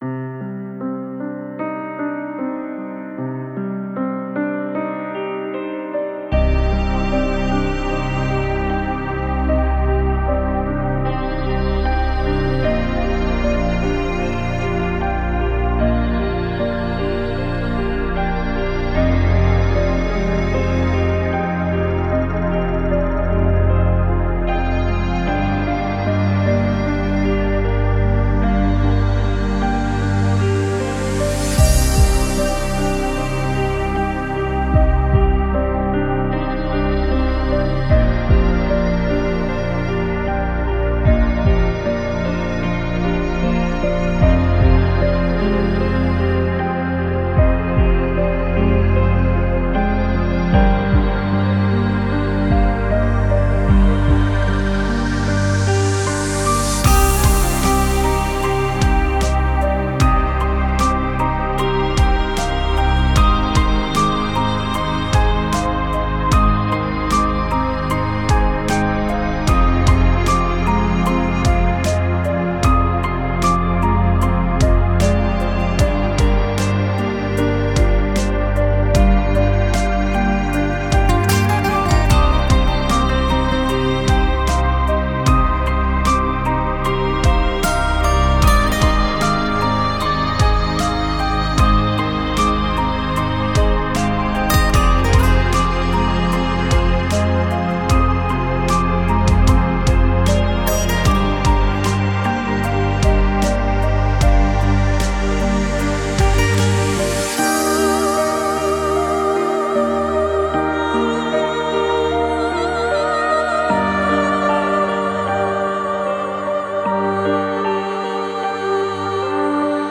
Стиль: Chillout / Lounge / Ambient / Downtempo